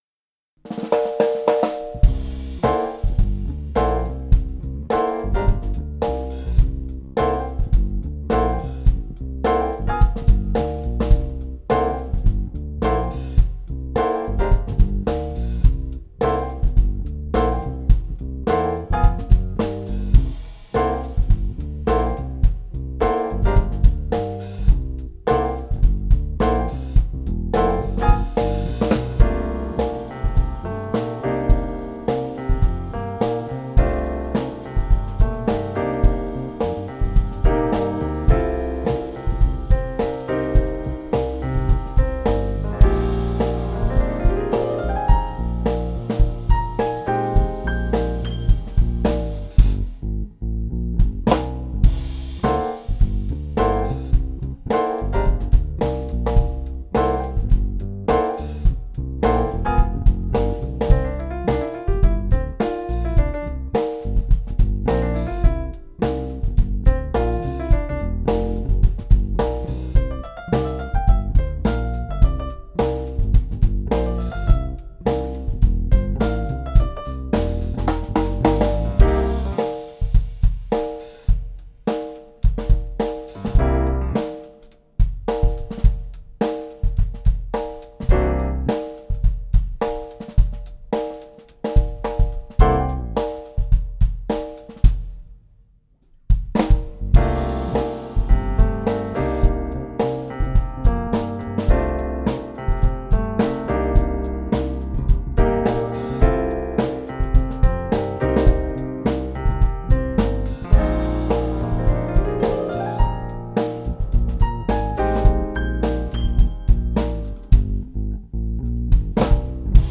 accomp trio jazz
t-as-pas-le-debit-accomp.trio-jazz.mp3